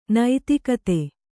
♪ naitikate